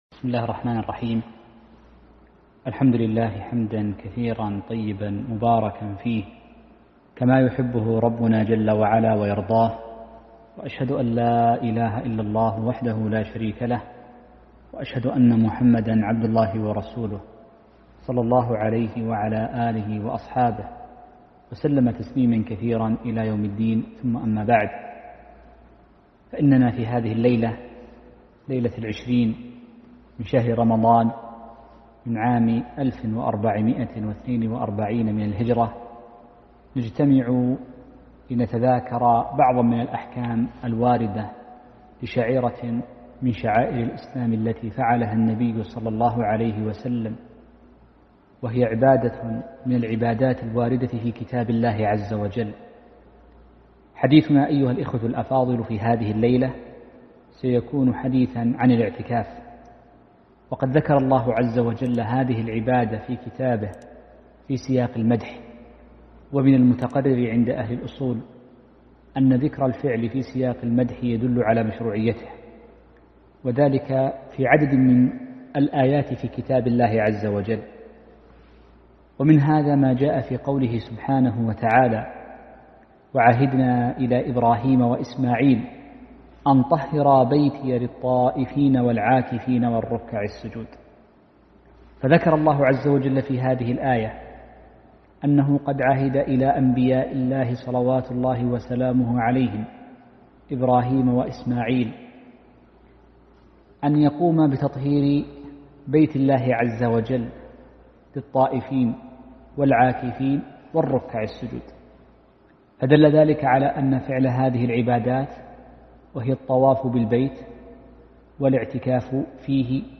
محاضرة - أحكام الاعتكاف ونوازله